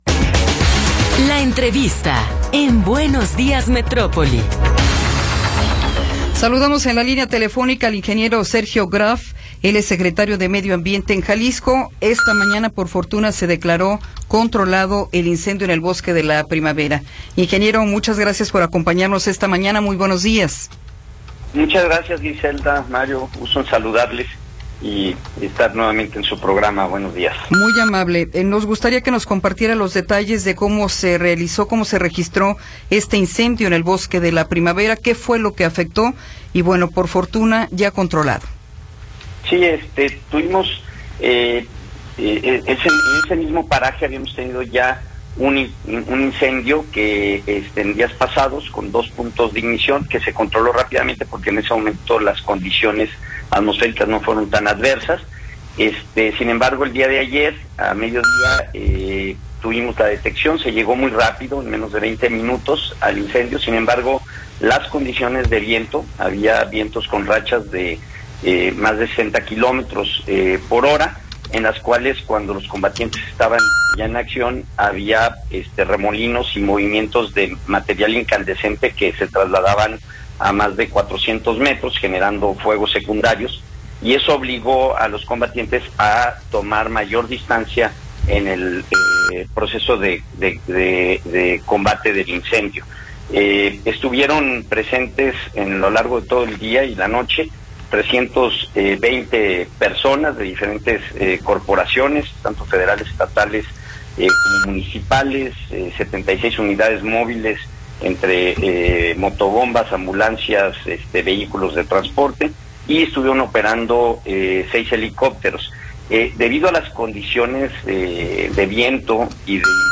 Entrevista con Sergio Graf Montero
Sergio Graf Montero, secretario de medio ambiente y desarrollo territorial de Jalisco, nos habla sobre el incendio registrado ayer en el bosque de La Primavera.